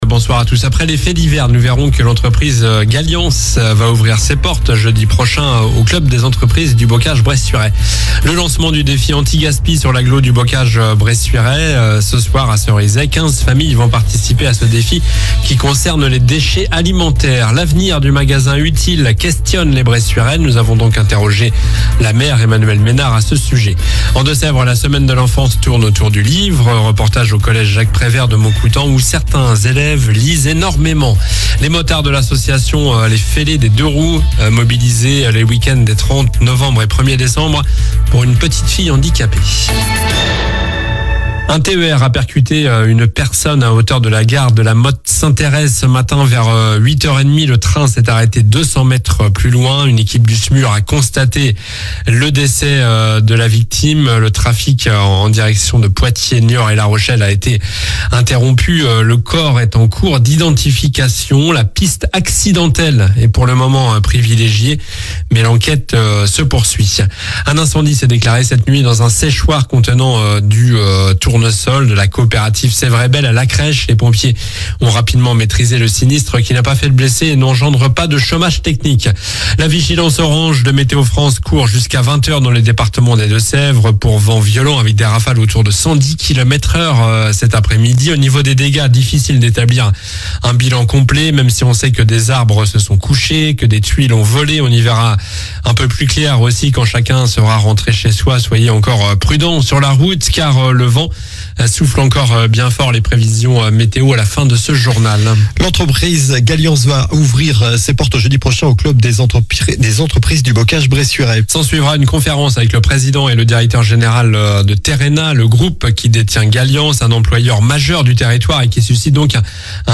Journal du jeudi 21 novembre (soir)